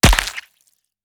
FruitHit.wav